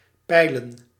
Ääntäminen
IPA : /ˈfæð.əm/ US : IPA : [ˈfæð.əm]